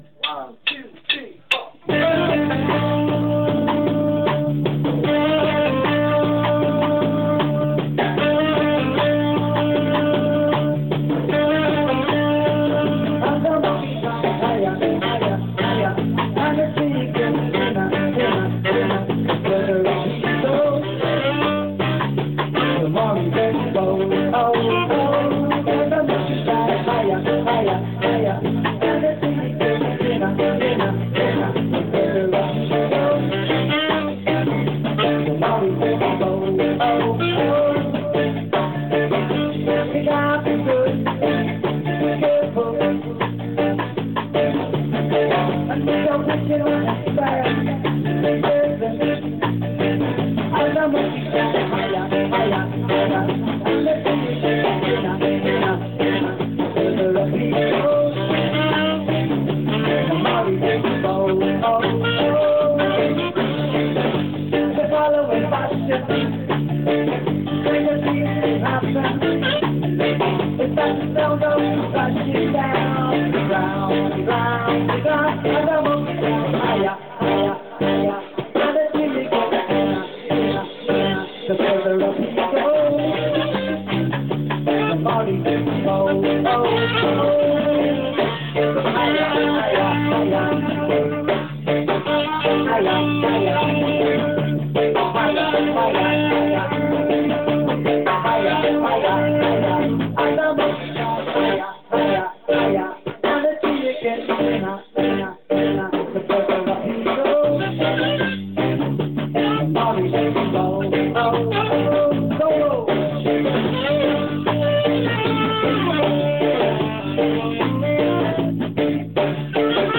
Monkey Factor (rehearsal 19 04 10)